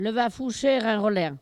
Localisation Barbâtre
Catégorie Locution